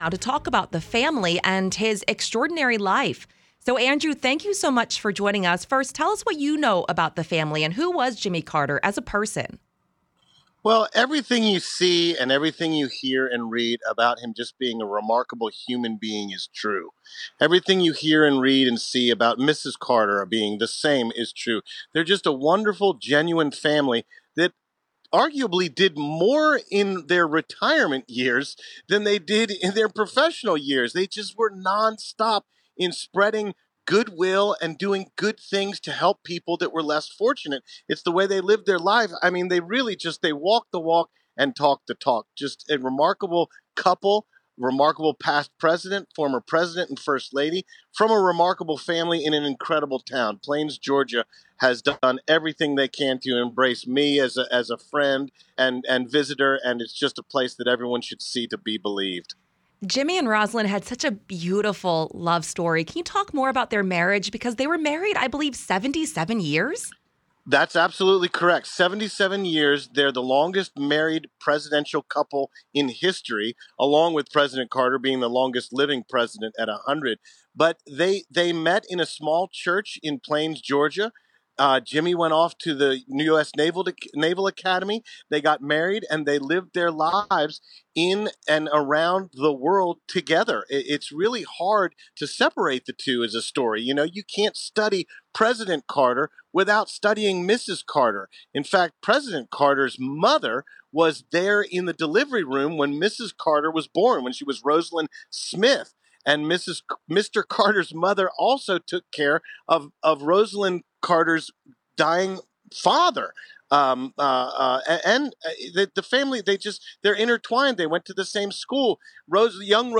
WTOP spoke with historians, political reporters and those who personally knew Carter as the nation mourned his death.